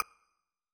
Raise-Your-Wand / Sound / Effects / UI / Retro1.wav